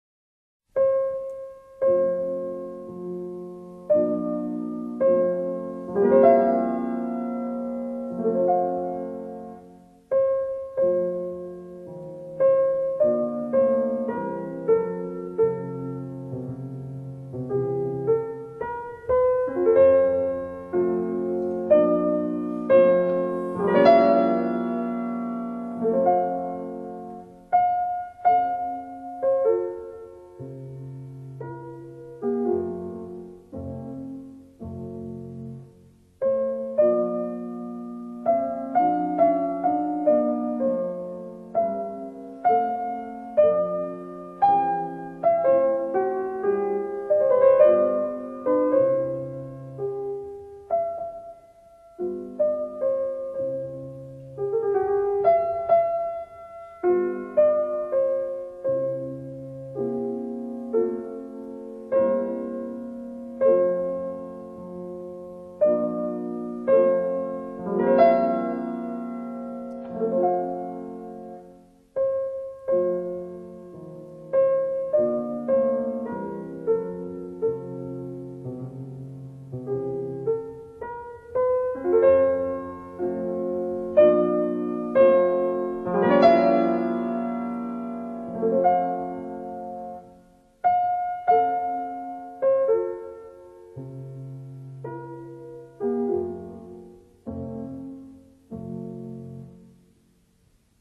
F大调6首钢琴变奏曲
D大调6首钢琴变奏曲
降E大调15首钢琴变奏曲与赋格